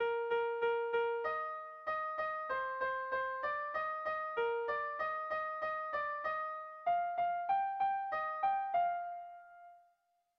Dantzakoa
Lauko txikia (hg) / Bi puntuko txikia (ip)
AB